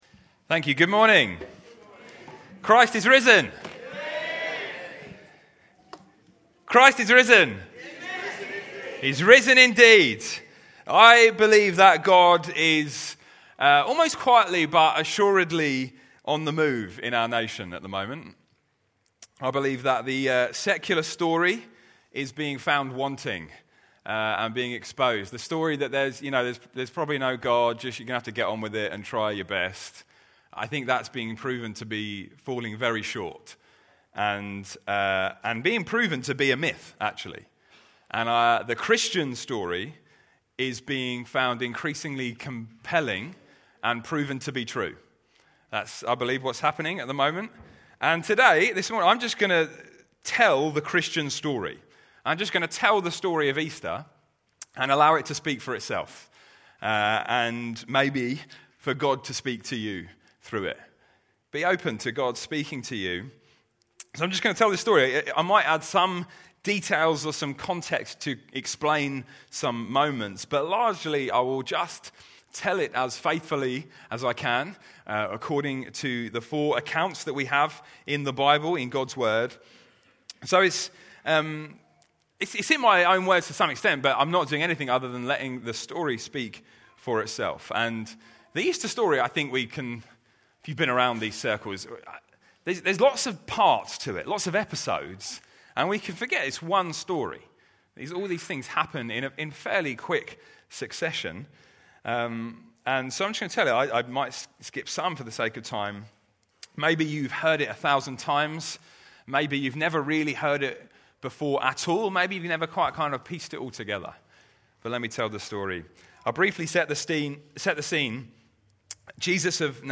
Easter Sunday 2025
Series: Other Sermons 2025